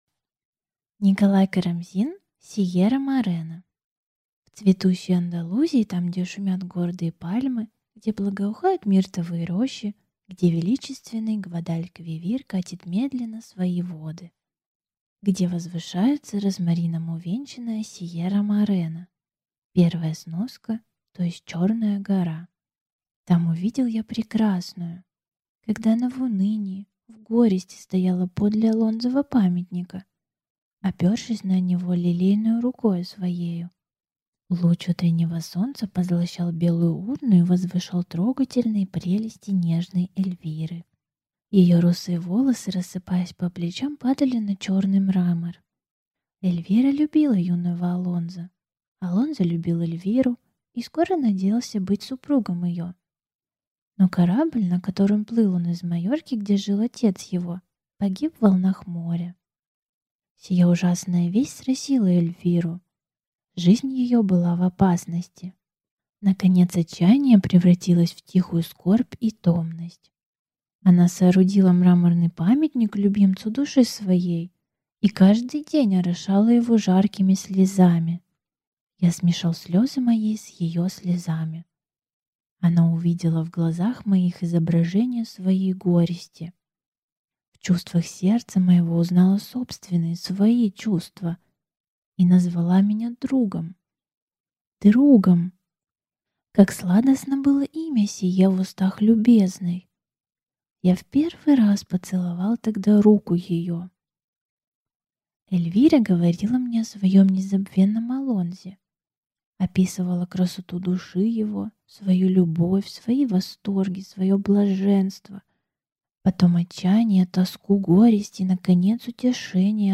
Аудиокнига Сиерра-Морена | Библиотека аудиокниг
Прослушать и бесплатно скачать фрагмент аудиокниги